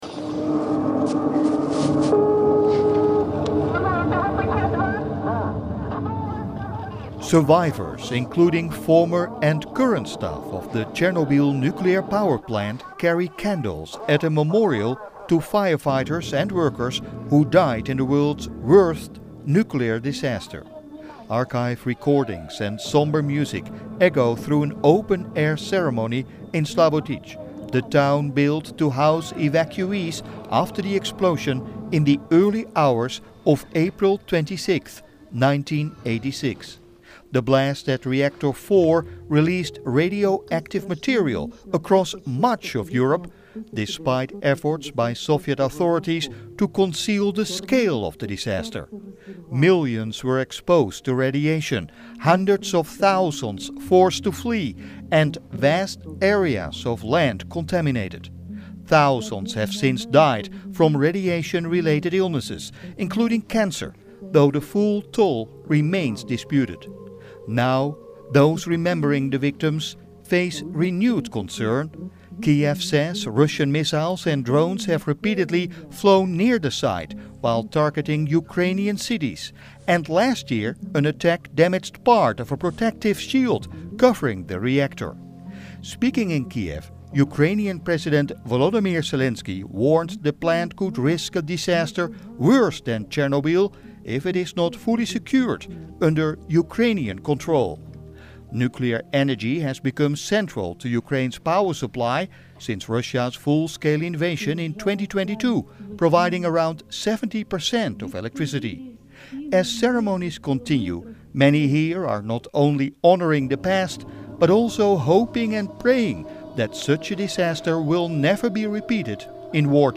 Archive recordings and somber music echoed through an open-air ceremony in Slavutych, the town built to house evacuees after the explosion in the early hours of April 26, 1986.